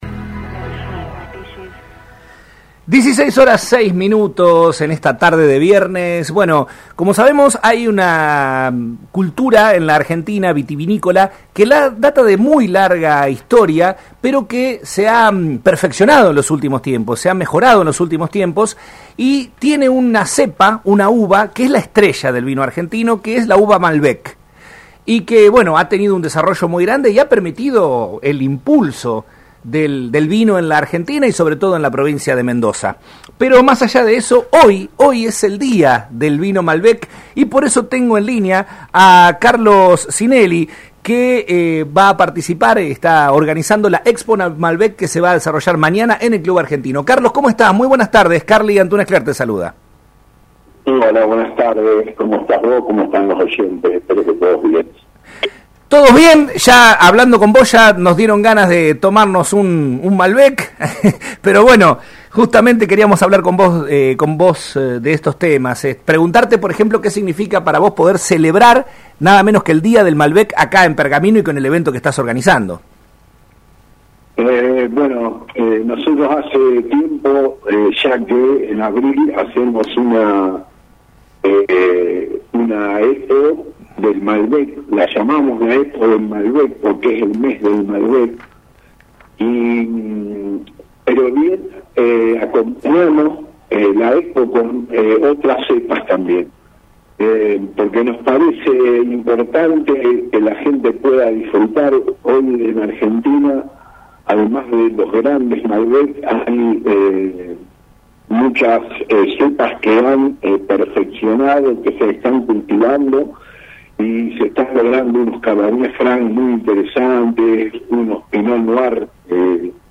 En diálogo con el programa Nuestro Tiempo de Radio Mon Pergamino AM 1540